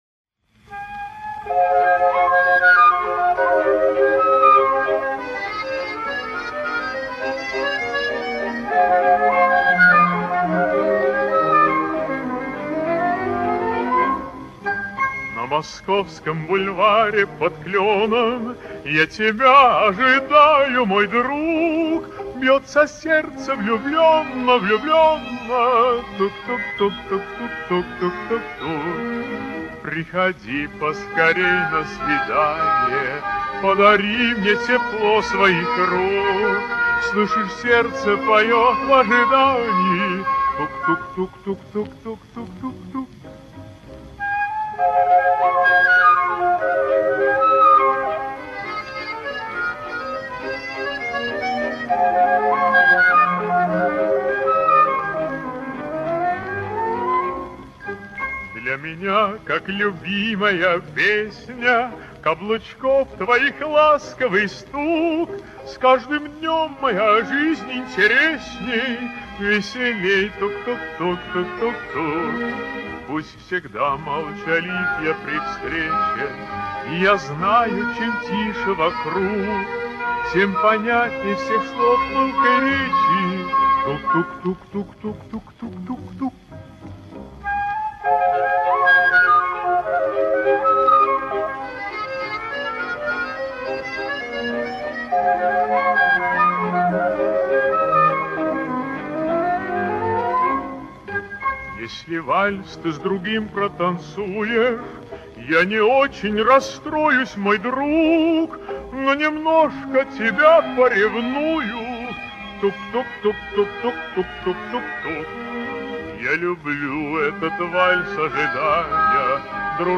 Эстонский певец (баритон), народный артист СССР (1960).